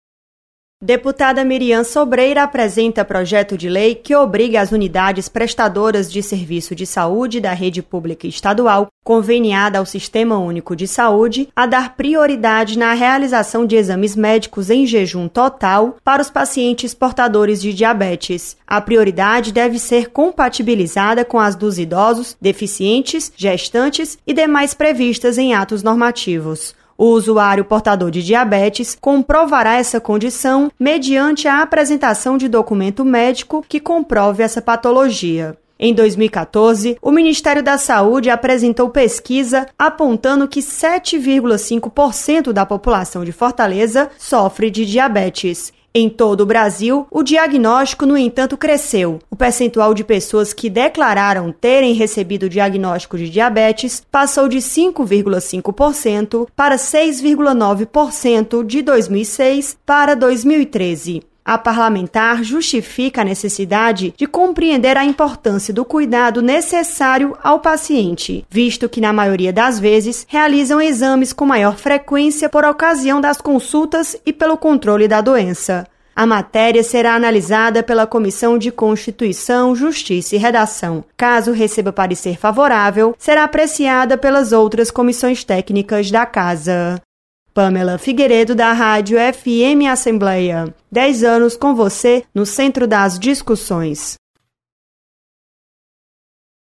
Projeto prevê prioridade para pacientes com diabetes na rede pública e saúde. Repórter